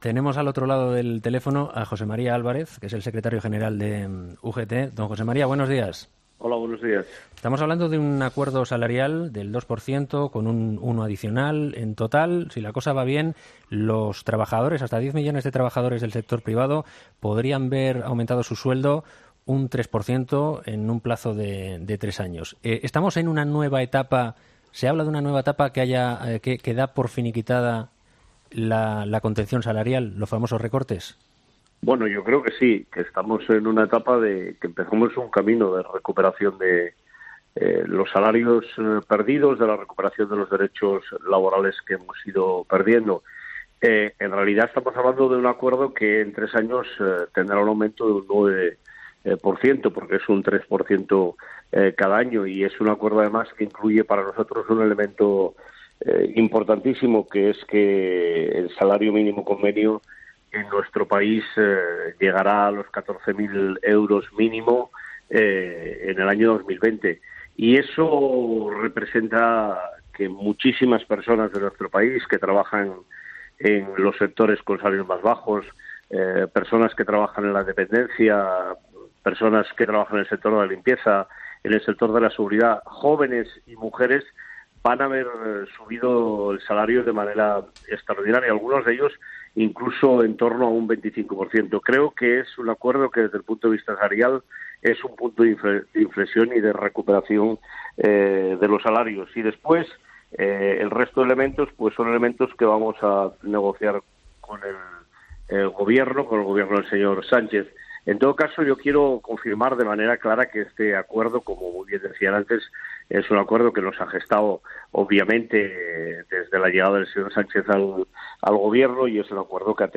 Escucha la entrevista al secretario general de UGT, Pepe Álvarez